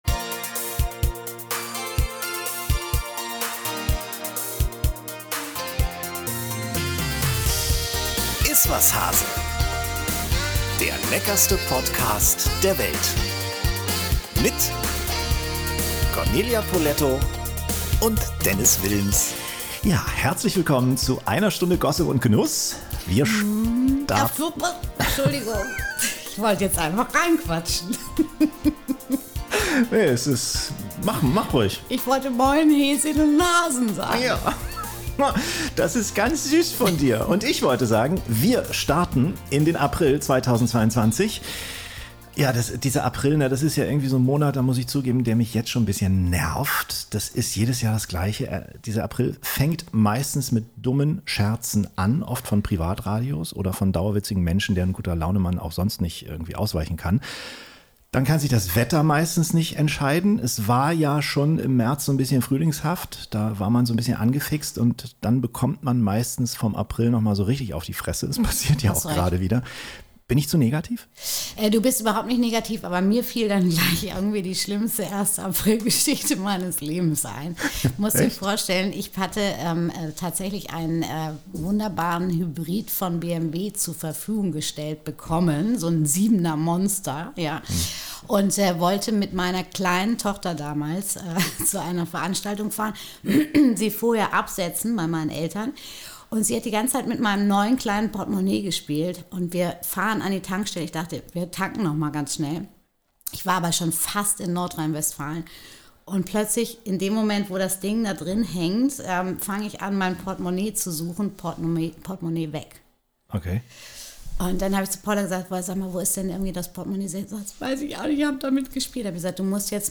Das ist man (und natürlich auch Frau) froh, wenn die ritualisierte Plauderei in einem Hamburger Podcaststudio für Behaglichkeit und ein wenig Glück sorgt.